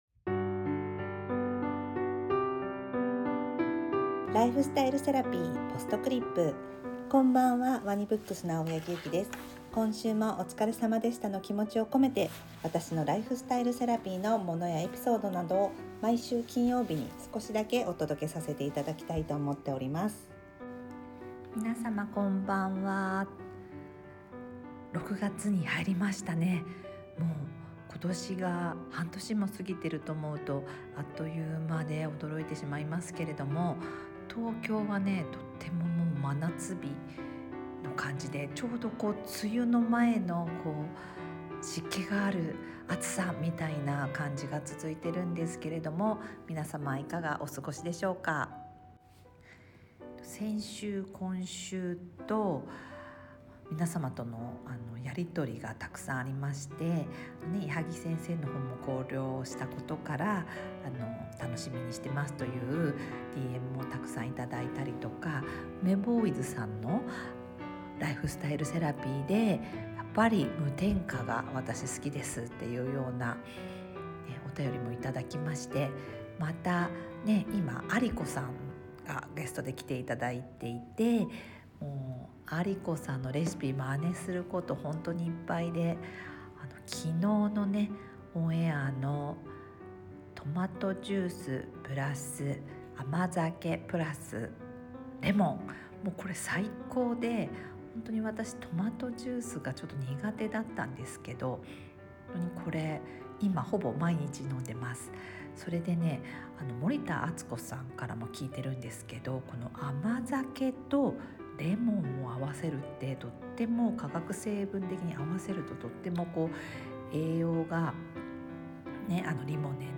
BGM／MusMus